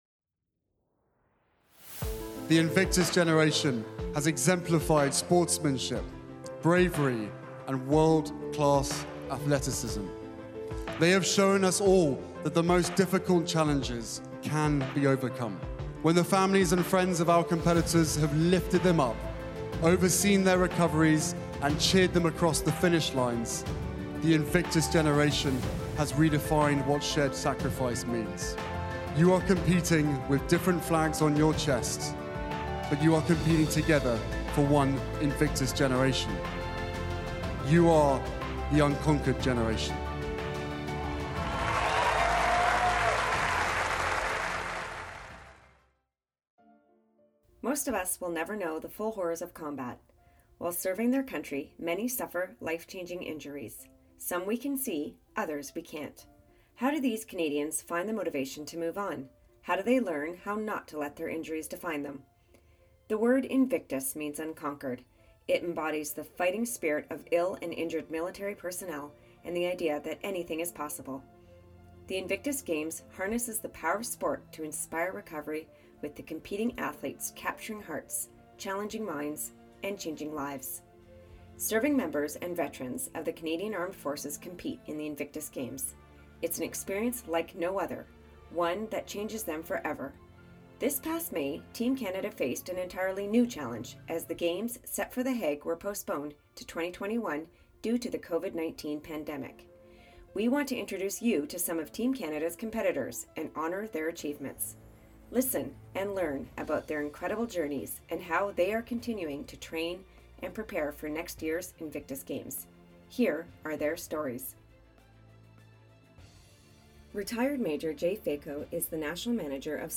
These podcasts were then recreated in the alternate official language using voiceovers.